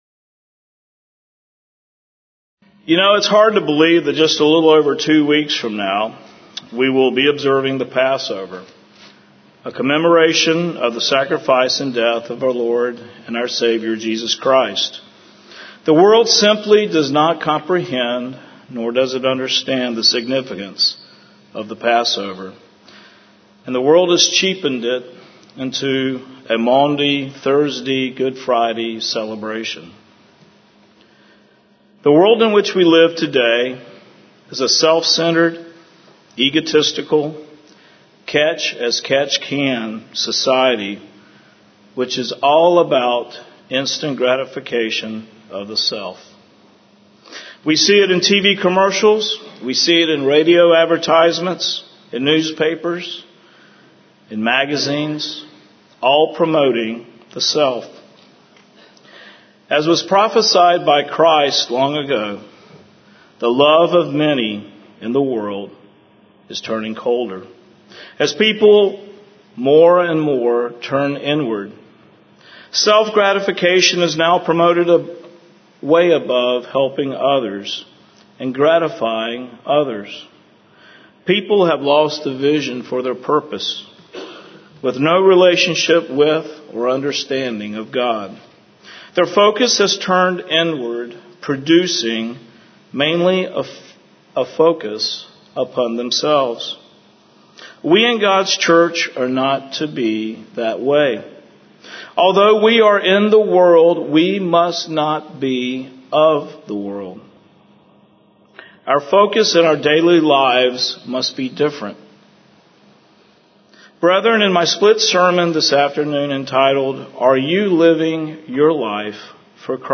Sermon
Given in Houston, TX